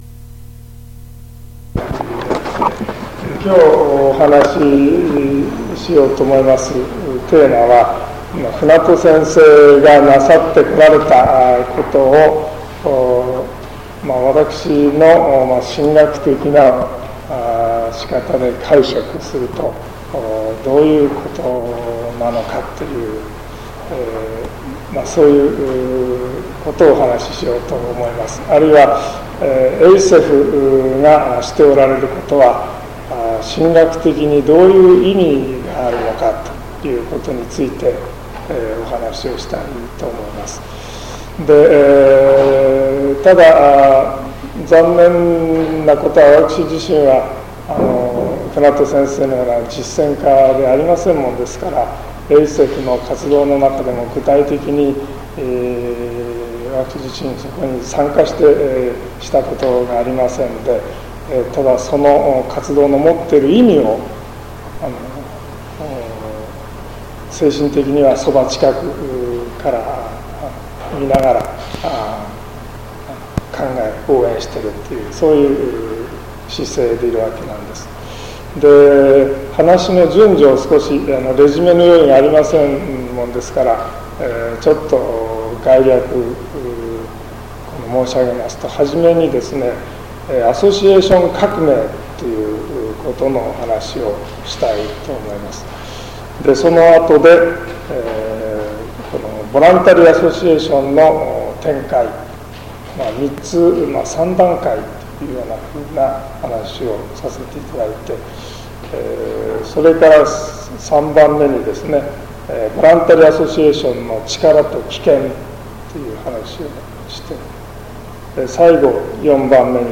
セミナー録音